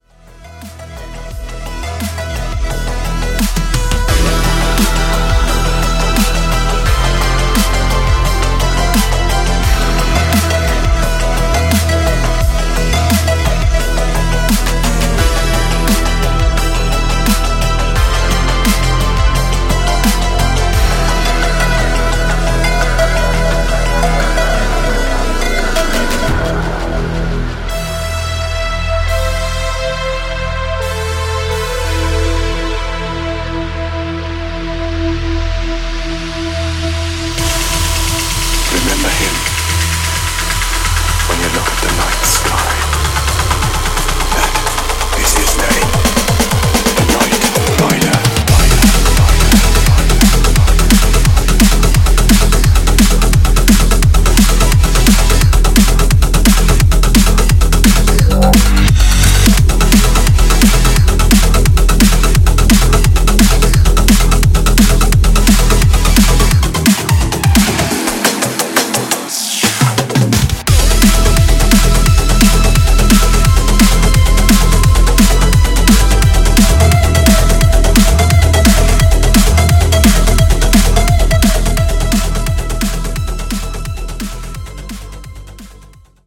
Styl: Dub/Dubstep, Drum'n'bass